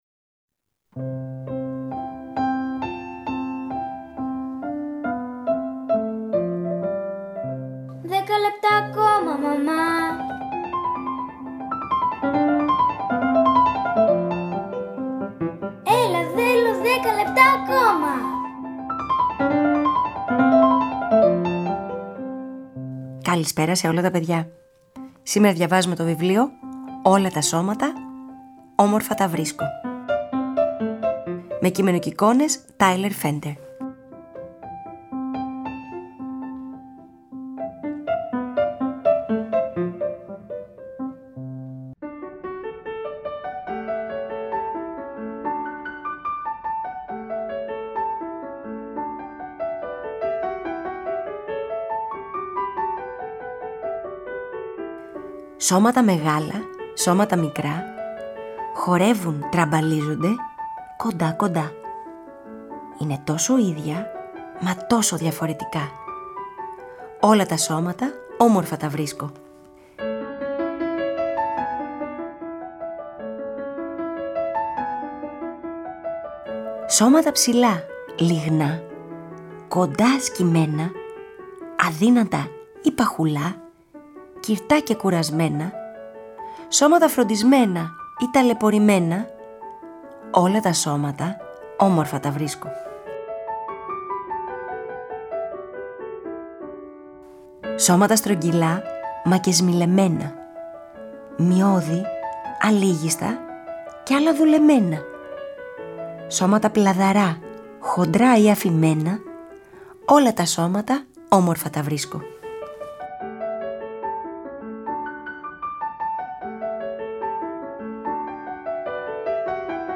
Αφήγηση-Μουσικές επιλογές: